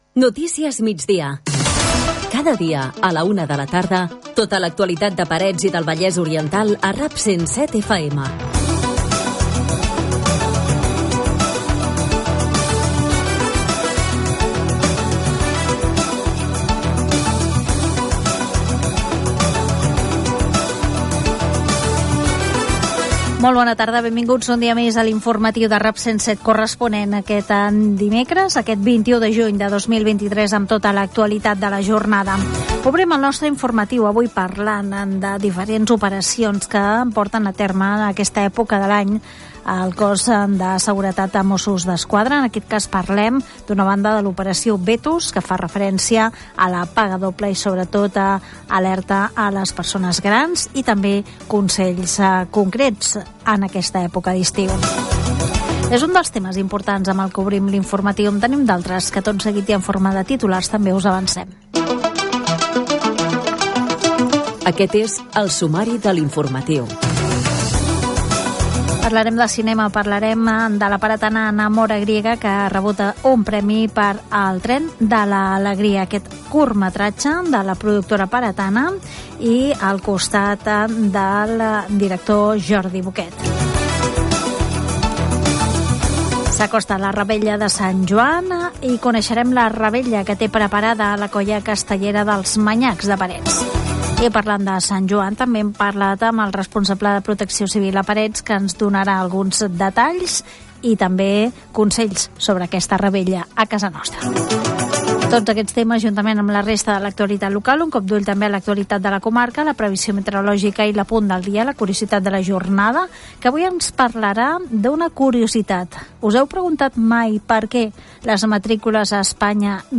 Careta del programa, data, sumari informatiu, indicatiu del programa Gènere radiofònic Informatiu